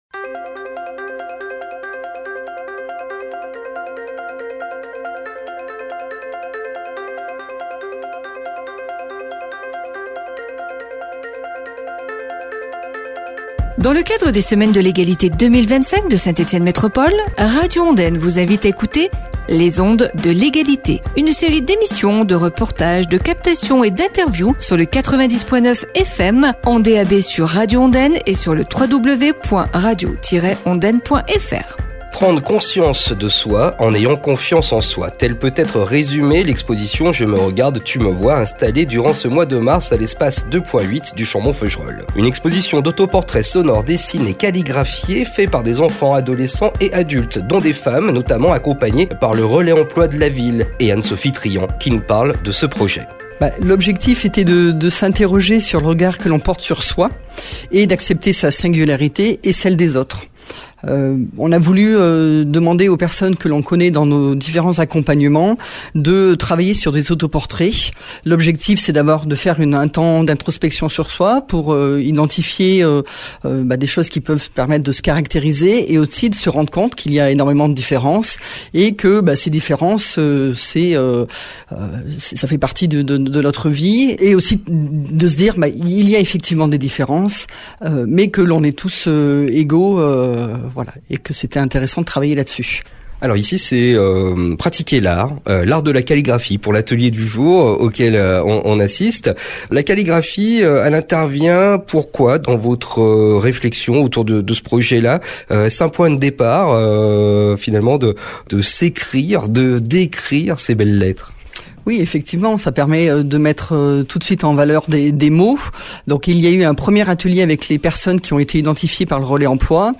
Radio Ondaine vous invite à écouté un reportage organisé lors d’une séance de calligraphie, pour préparer cette exposition